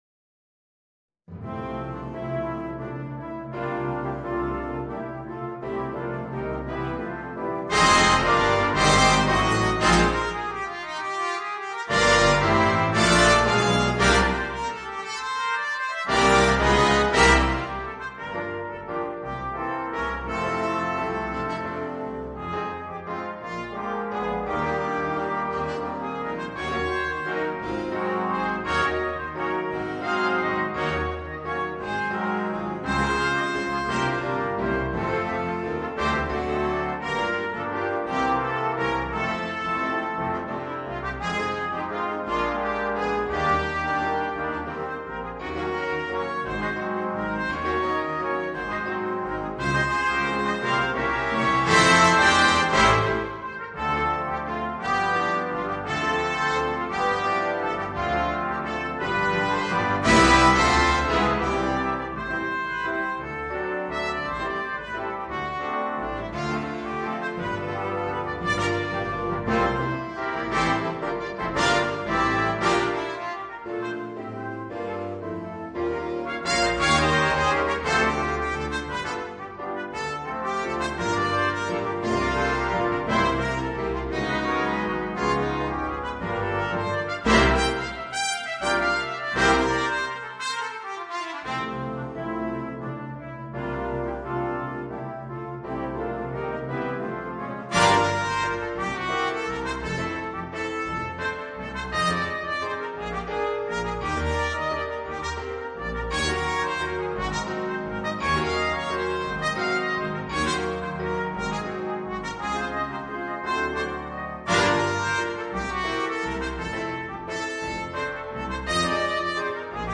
Voicing: Violin and Big Band